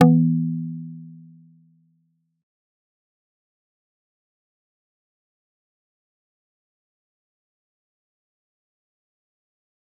G_Kalimba-E3-mf.wav